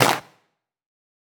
pcp_clap07.wav